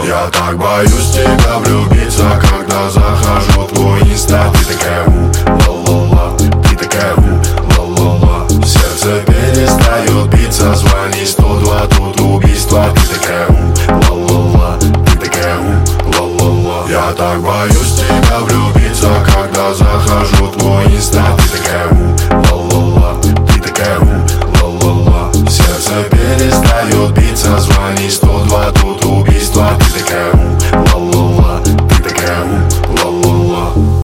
• Качество: 128, Stereo
поп